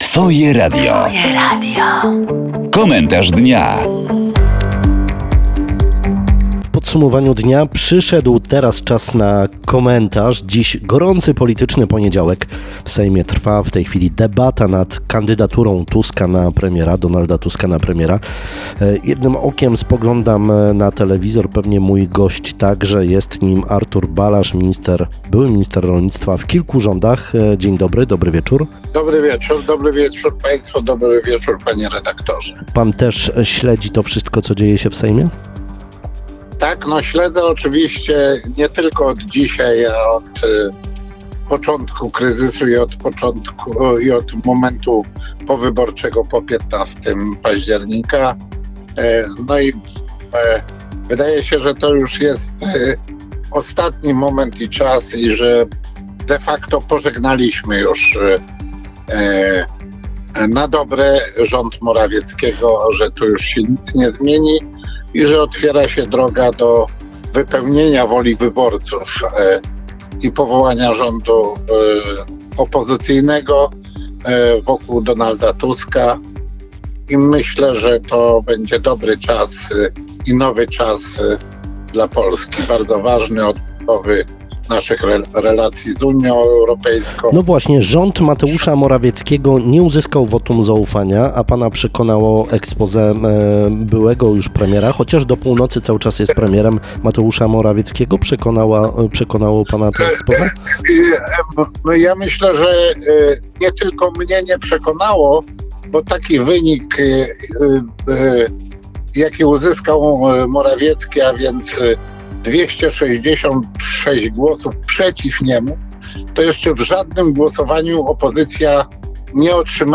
Sejm wybrał Donalda Tuska na nowego premiera. Artur Balazs – gość popołudniowego Komentarza Dnia, twierdzi, że Prawo i Sprawiedliwość do tej pory nie zrozumiało, dlaczego przegrało wybory, a większość parlamentarną ma koalicja.